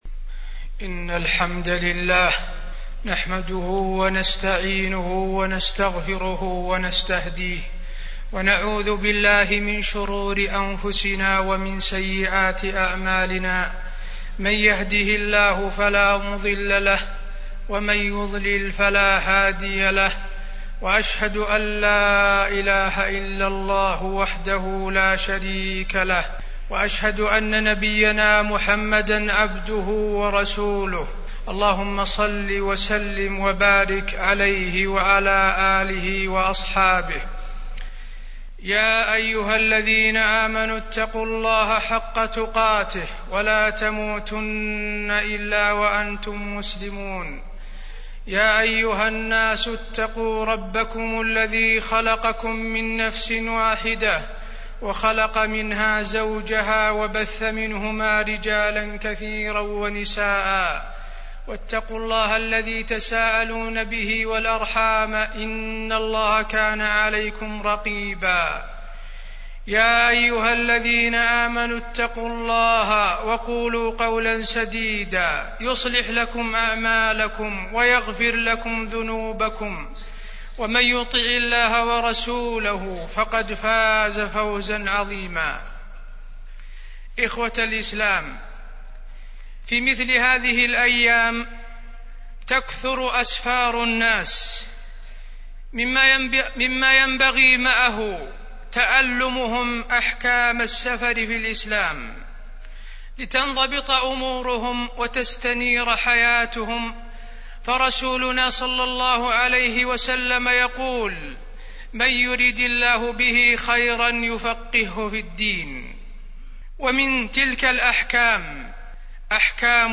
تاريخ النشر ٢٠ جمادى الأولى ١٤٢٧ هـ المكان: المسجد النبوي الشيخ: فضيلة الشيخ د. حسين بن عبدالعزيز آل الشيخ فضيلة الشيخ د. حسين بن عبدالعزيز آل الشيخ أحكام السفر